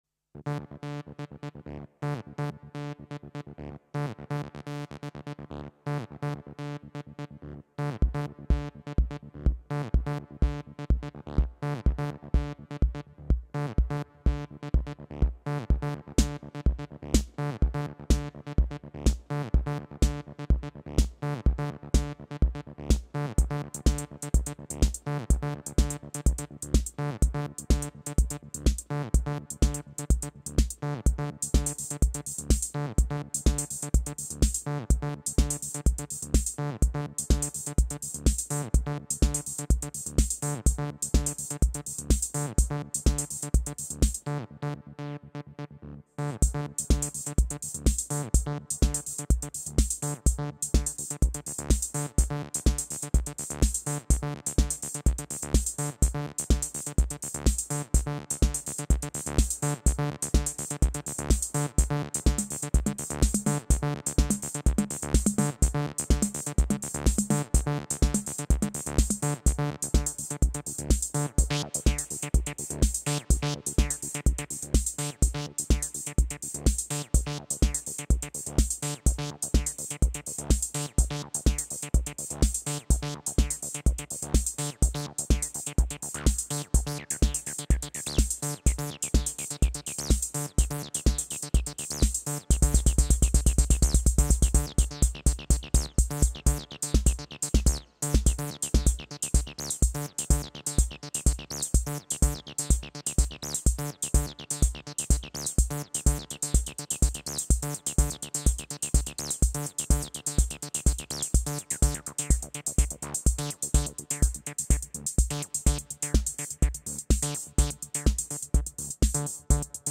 Friday night jamming on the Behringer plastic acid boxes rd6 and td-3-mo. I spent ages making a tune on the Eurorack but it turned out awful so I jumped on the plastic guys and freestyled!
Plastic-Acid.mp3